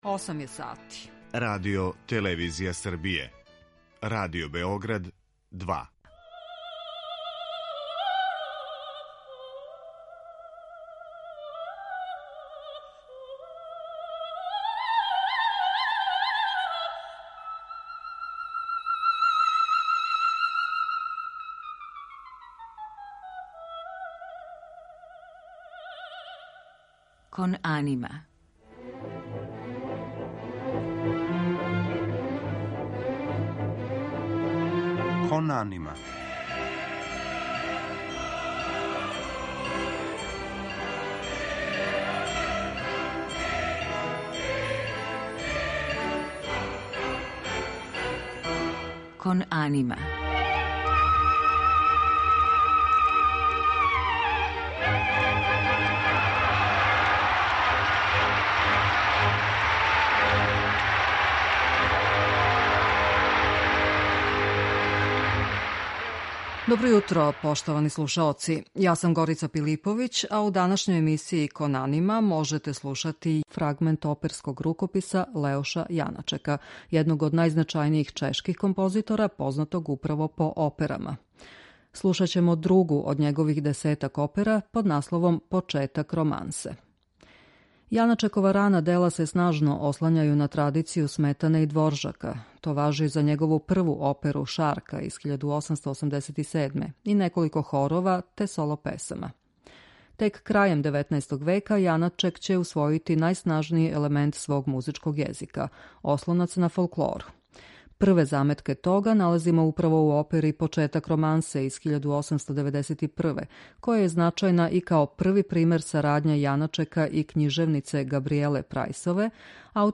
Слушаћемо друго од његових десетак остварења овог жанра под насловом „Почетак романсе".